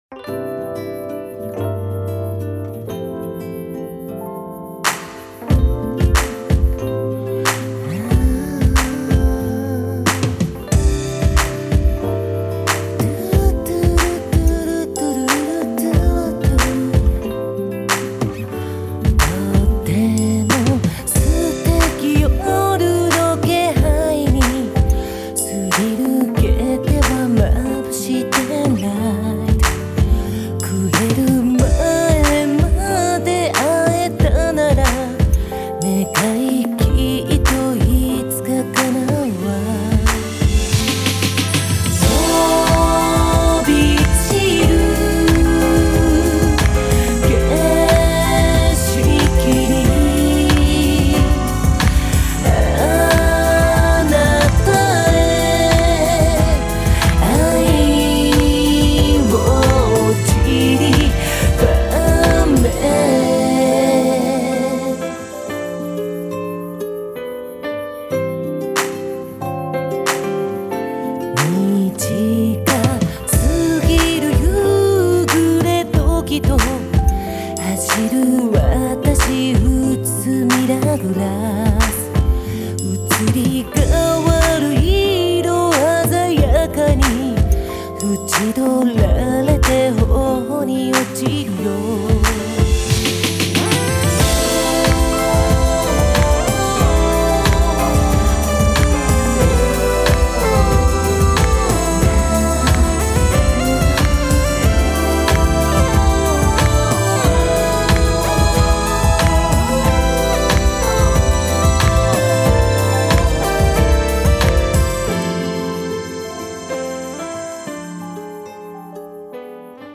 原曲を仰ぎ見つつ歌い流す声と、柔らかに揺れるエレピがマッチしたメロウなナンバー
ジャンル(スタイル) JAPANESE POP